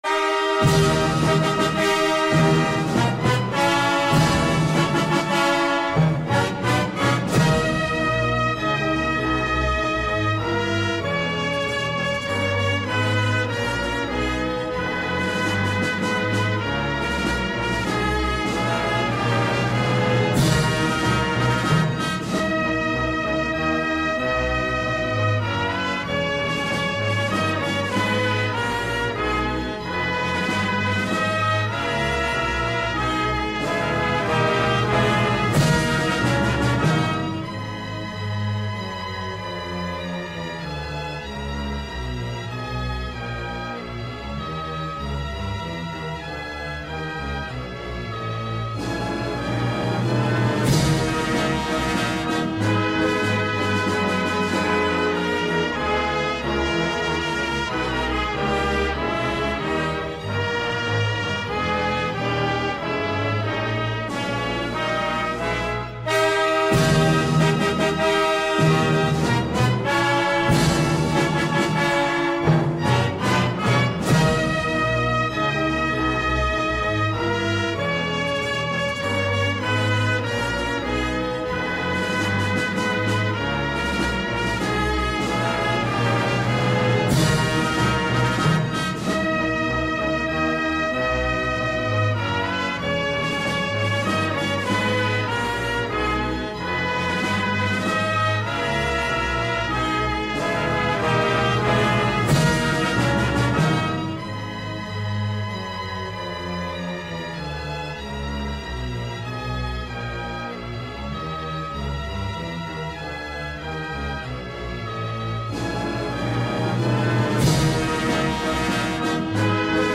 • Качество: высокое
Мелодия передает глубину традиций и любовь к родной земле.
инструментальная версия